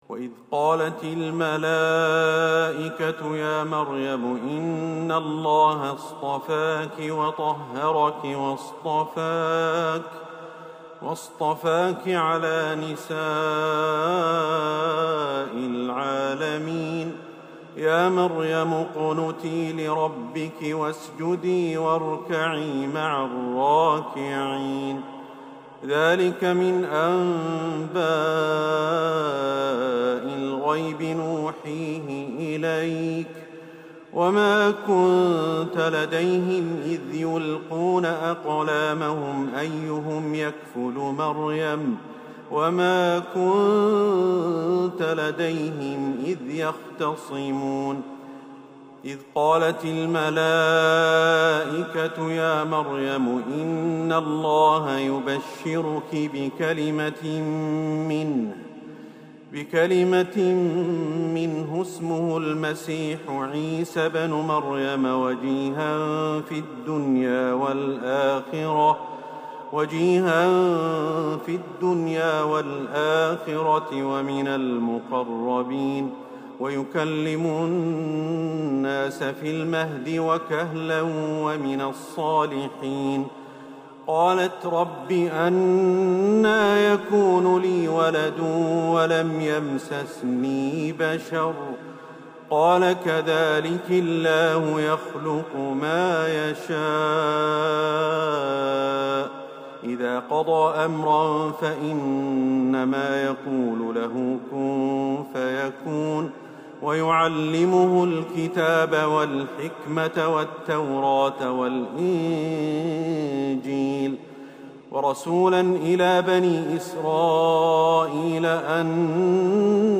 تراويح ليلة 4 رمضان 1447هـ من سورة آل عمران {42-92} Taraweeh 4th night Ramadan 1447H Surah Aal-i-Imraan > تراويح الحرم النبوي عام 1447 🕌 > التراويح - تلاوات الحرمين